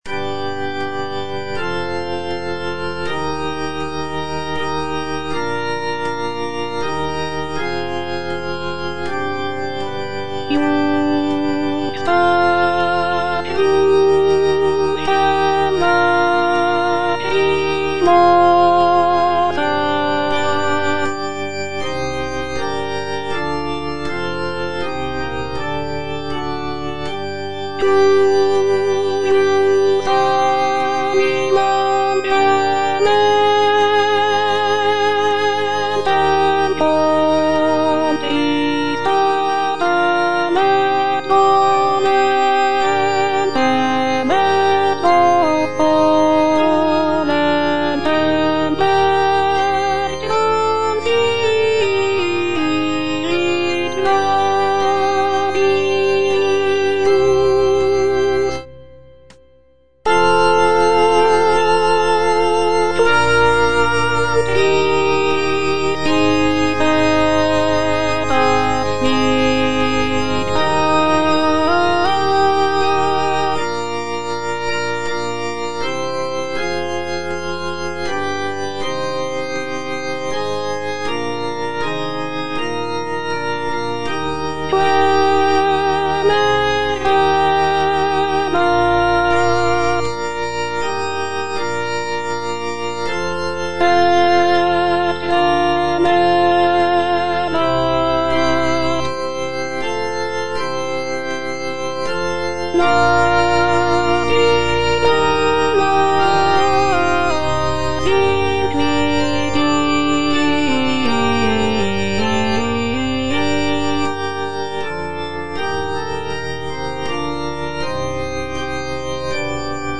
G.P. DA PALESTRINA - STABAT MATER Stabat Mater dolorosa (alto II) (Voice with metronome) Ads stop: auto-stop
sacred choral work